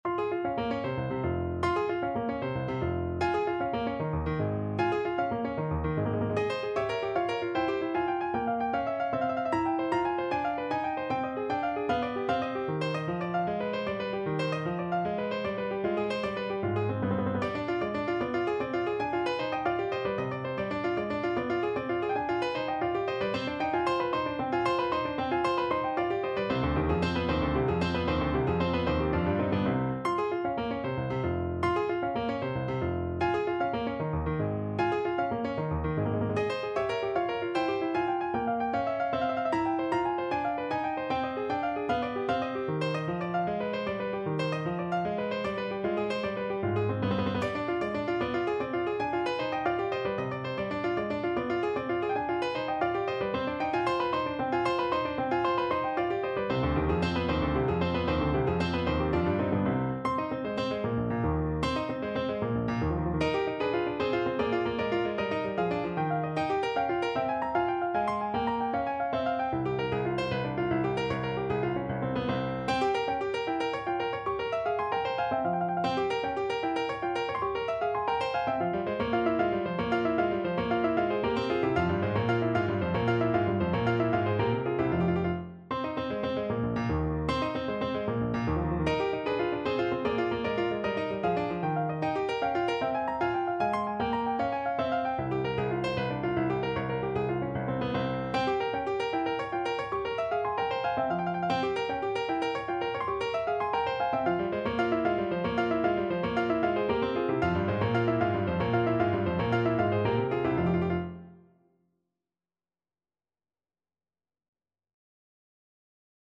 No parts available for this pieces as it is for solo piano.
Molto allegro .=152